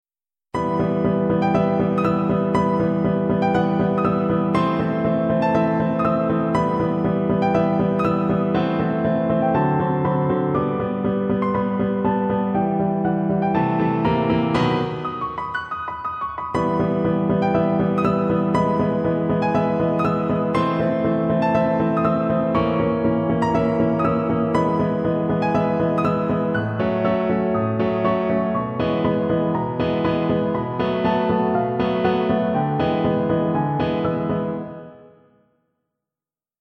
mp3Douglass, John Thomas, The Pilgrim, Grand Overture, Allegro Vivace, mm.13-29